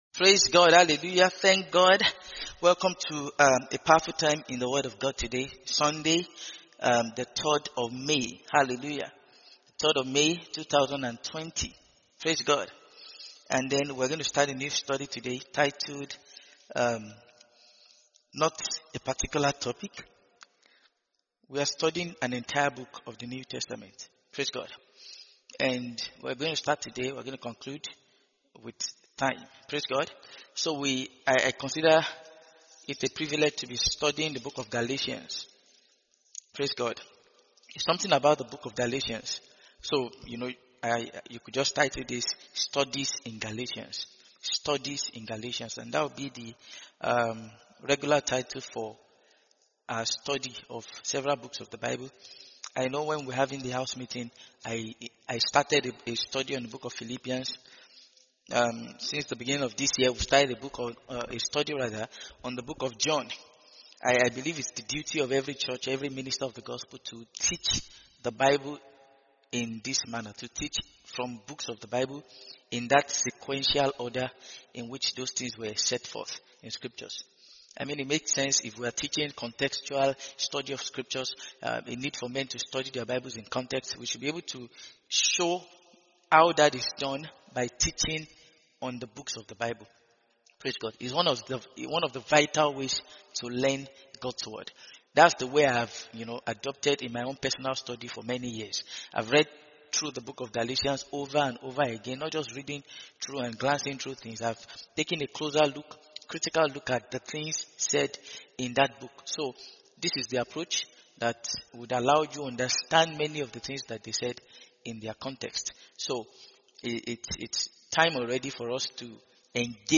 Mid-Week Sermons